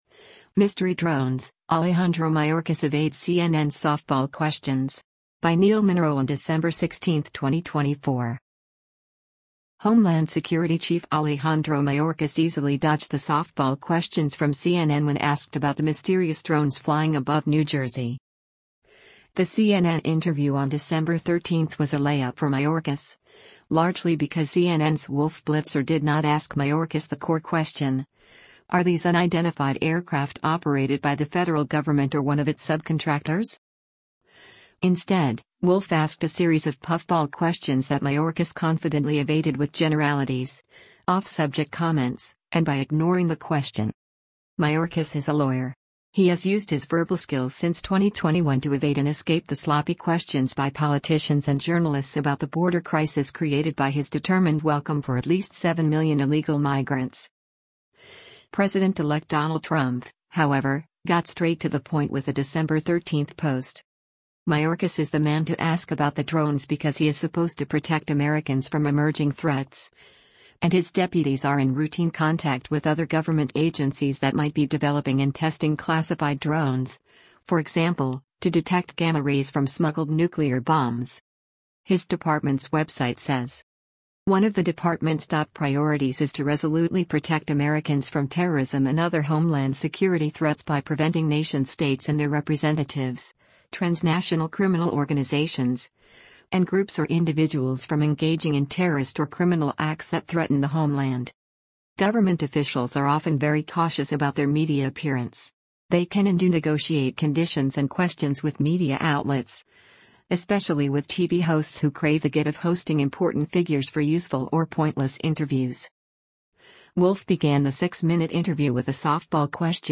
The CNN interview on December 13 was a layup for Mayorkas, largely because CNN’s Wolf Blitzer did not ask Mayorkas the core question: Are these unidentified aircraft operated by the federal government or one of its subcontractors?
“Let me calm those nerves,” Mayorkas condescendingly responded as he refused to admit, describe, or explain the visible aircraft: